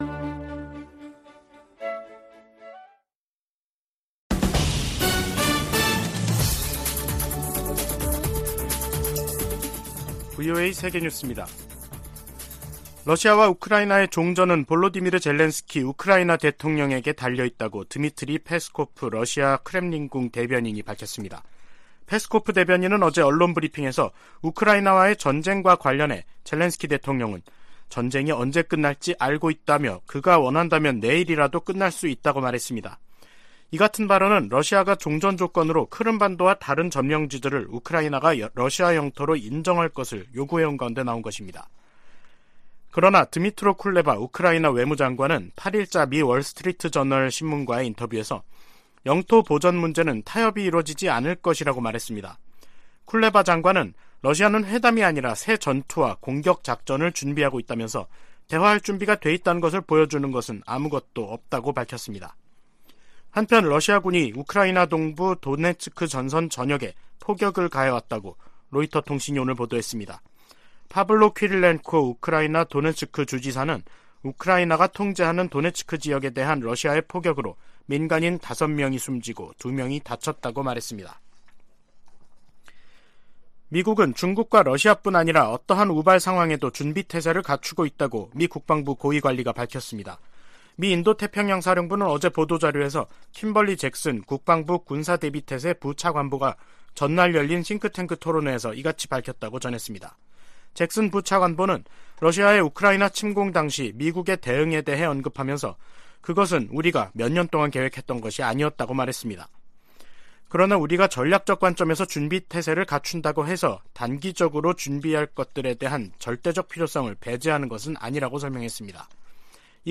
VOA 한국어 간판 뉴스 프로그램 '뉴스 투데이', 2022년 12월 9일 2부 방송입니다. 7차 핵실험 가능성 등 북한 김씨 정권의 핵 위협이 미국의 확장억지와 핵우산에 도전을 제기하고 있다고 백악관 고위관리가 지적했습니다. 국무부 대북특별대표가 중국 북핵 수석대표와의 화상회담에서 대북 제재 이행의 중요성을 강조했습니다.